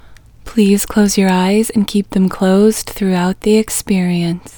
LOCATE IN English Female 1